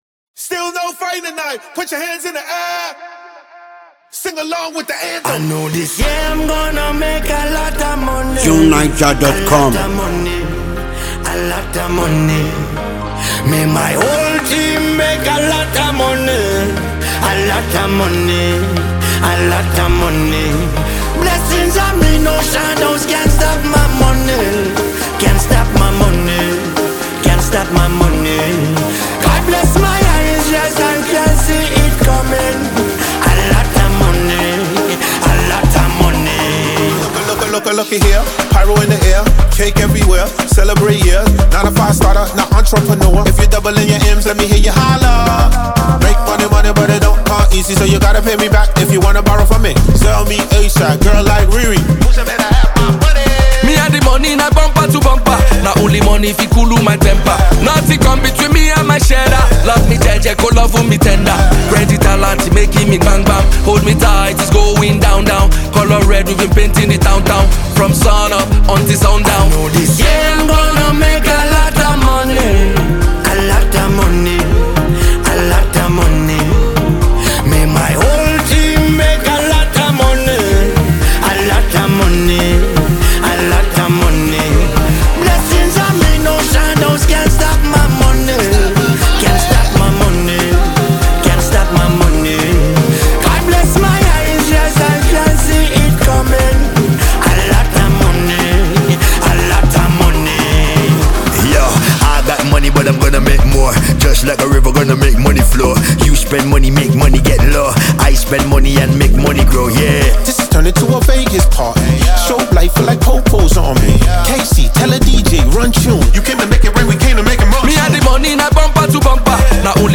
highlife/Afrobeats
catchy song